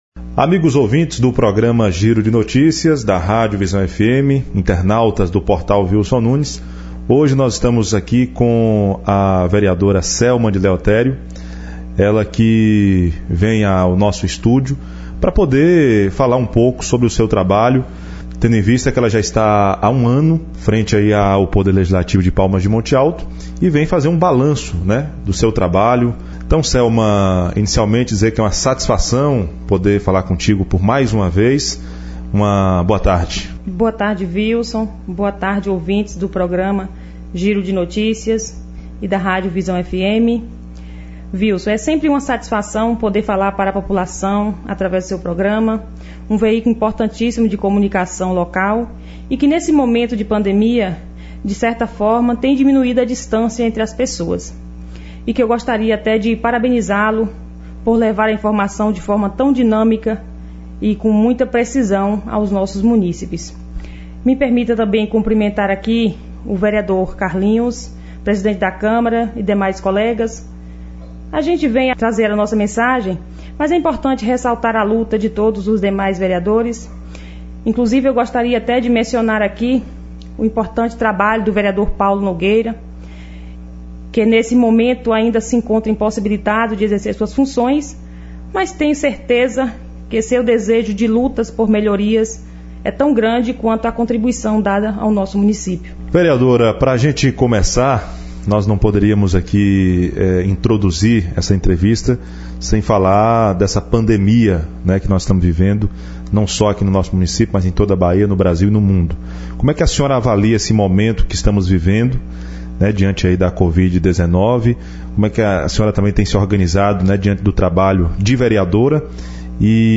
Nesta quinta-feira (4), em entrevista ao programa Giro de Notícias, da Rádio Visão FM, a Vereadora Selma de Leotério, após completar um ano de vereança na Câmara Municipal de Palmas de Monte Alto, falou aos monteltenses sobre sua experiência na vida pública e destacou ainda algumas de suas solicitações feitas com base nas demandas da população.
ENTREVISTA-DE-SELMA-DE-LEOTERIO-site.mp3